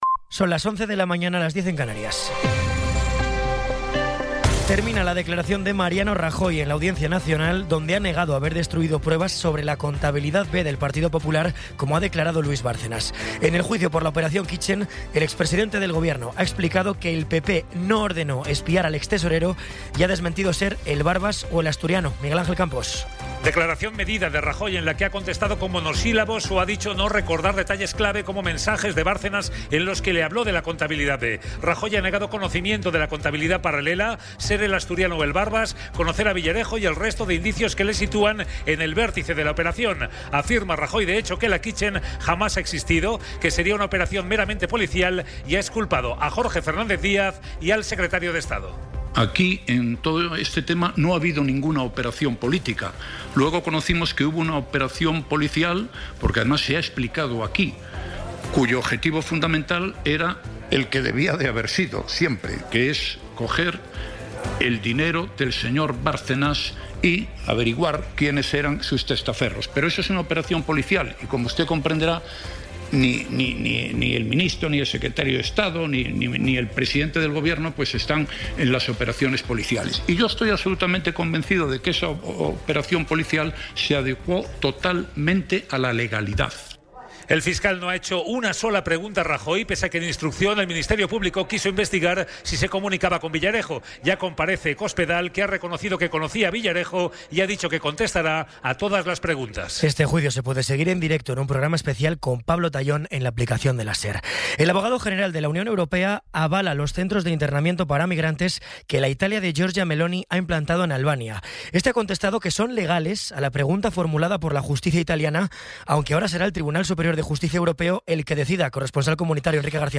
Resumen informativo con las noticias más destacadas del 23 de abril de 2026 a las once de la mañana.